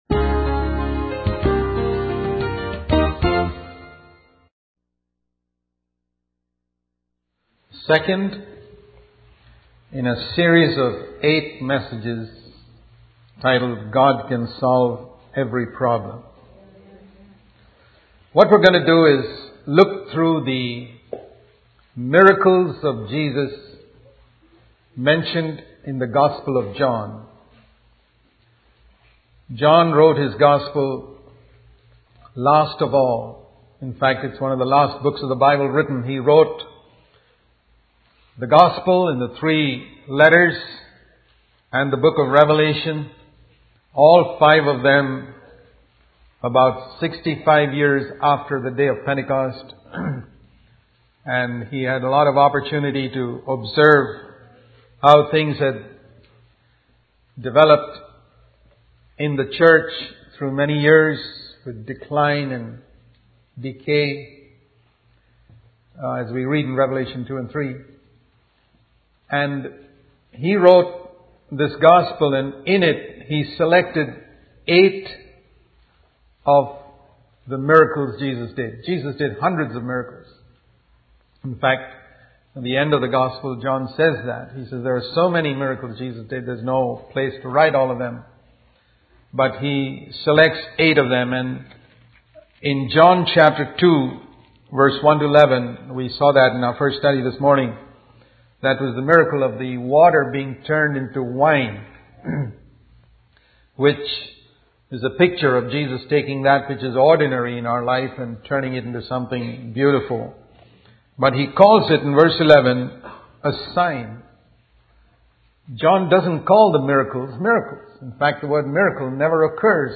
In this sermon, the speaker focuses on the story of a royal official whose son was healed by Jesus.